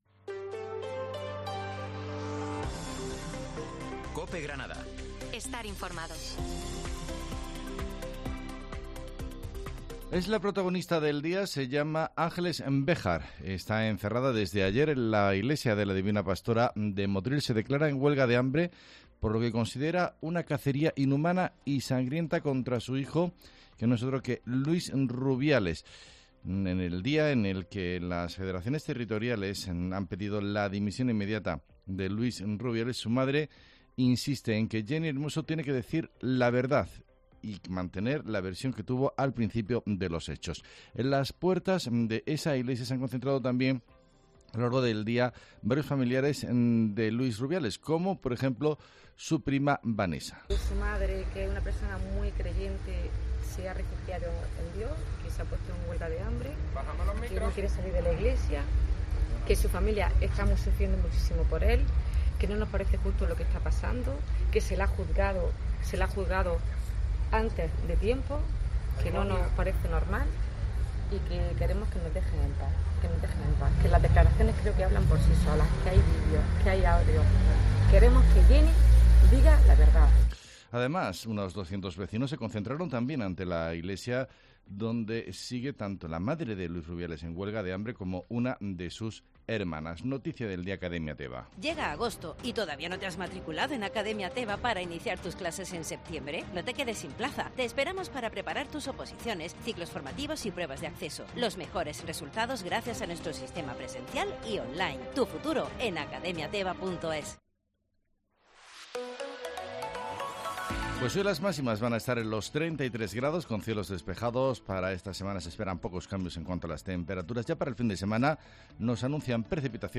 Herrera en COPE Granada, Informativo del 29 de agosto